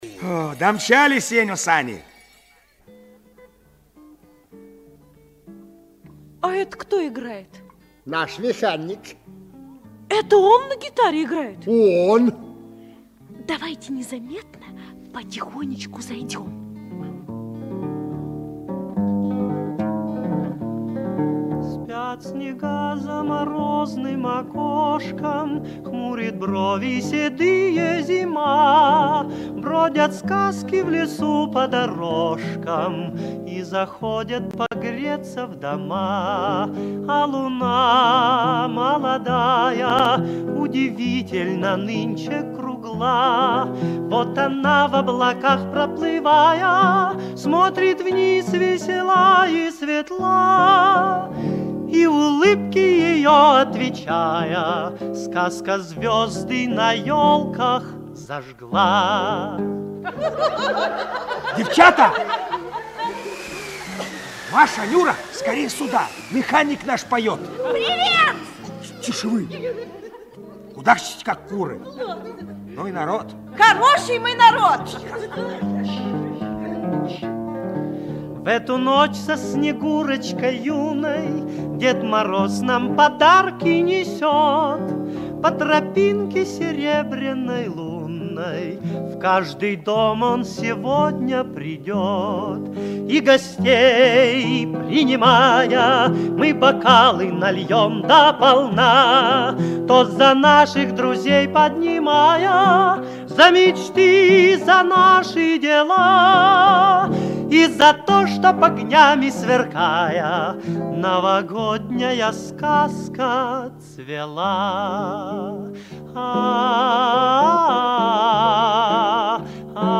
??? - Наш механик играет на гитаре и поёт, 1953
Фрагмент радиопередачи 1953 года